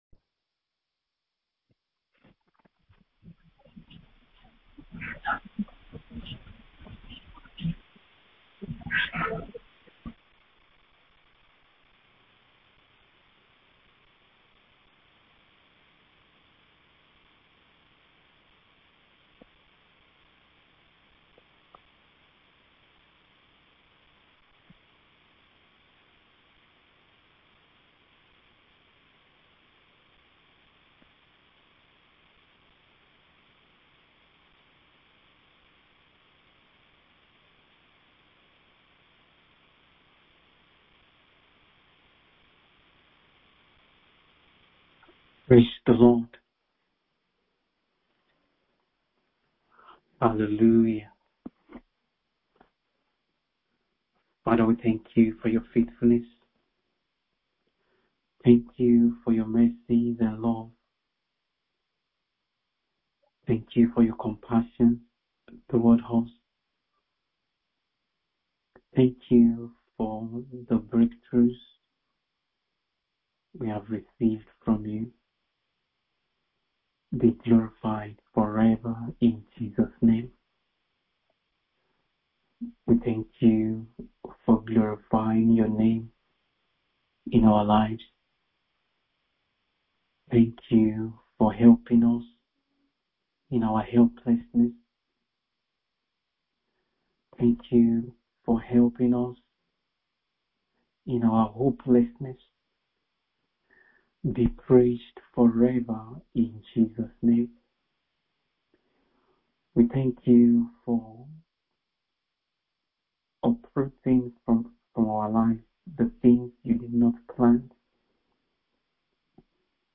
MIDNIGHT PRAYER SESSION :15 FEBRUARY 2025